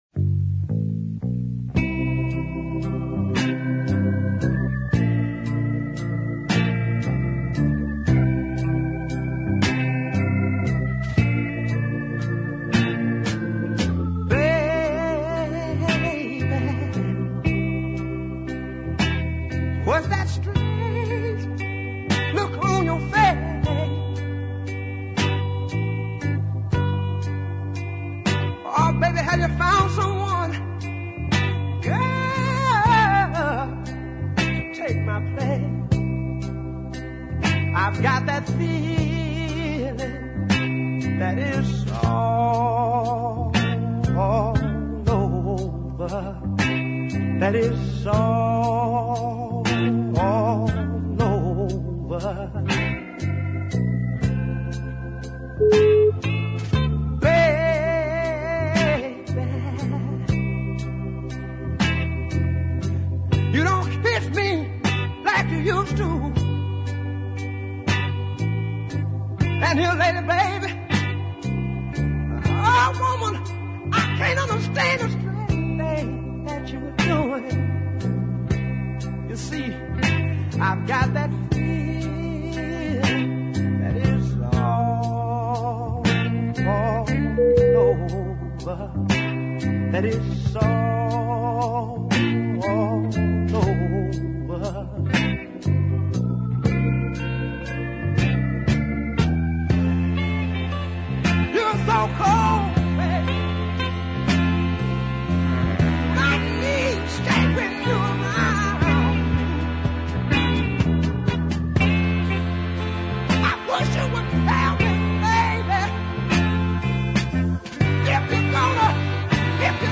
A southern soul cult figure